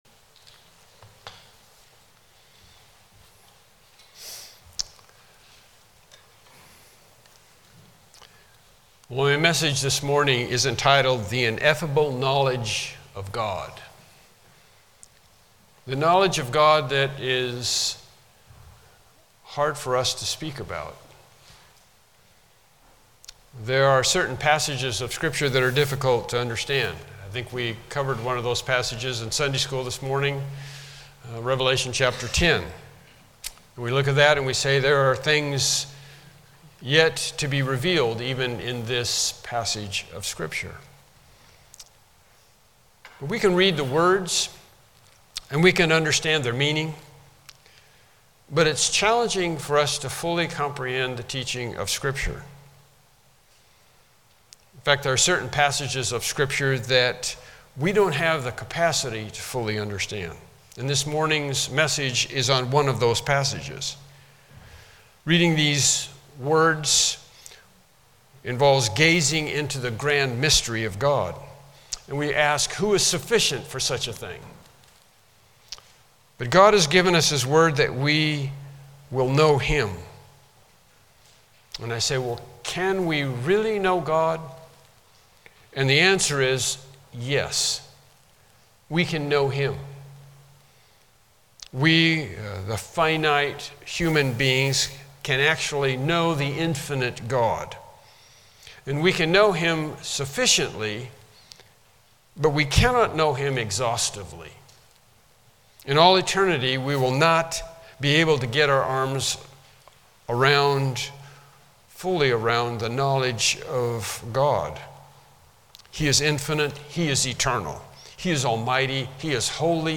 Mark 14:32-36 Service Type: Morning Worship Service « Lesson 10